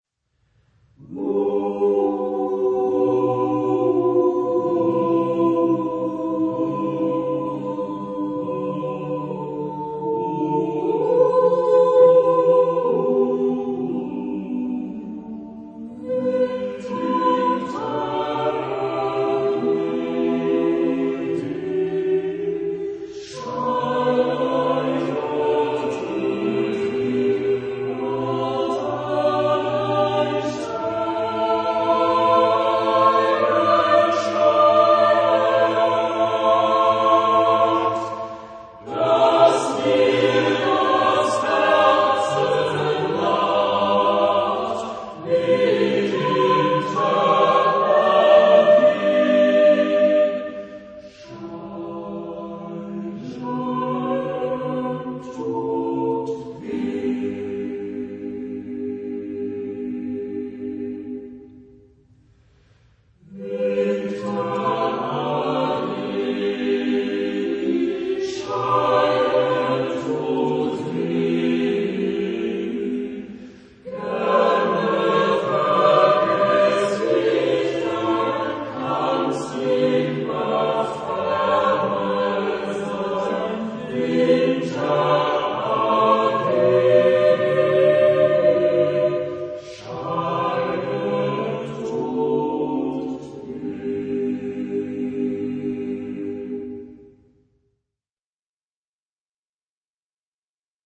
Genre-Style-Forme : Folklore ; Madrigal ; Profane
Type de choeur : SATB  (4 voix mixtes )
Tonalité : sol majeur